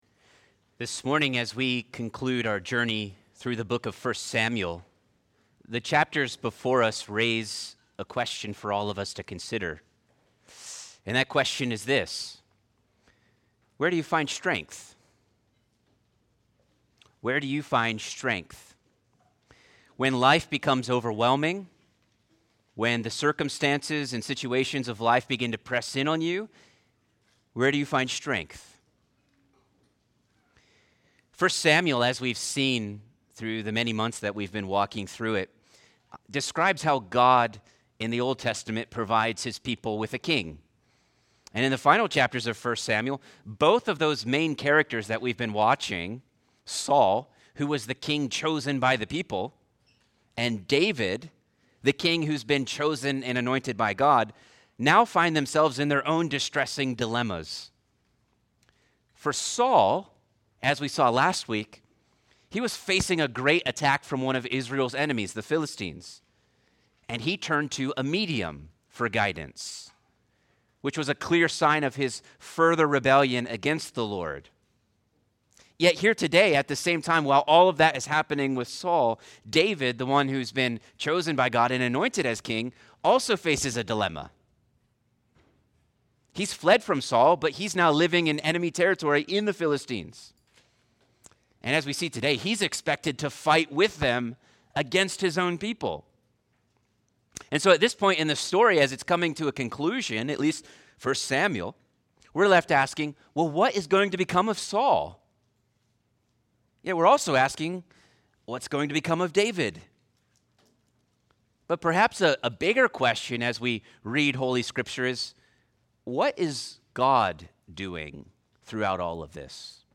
Sermons from Lovettsville Baptist Church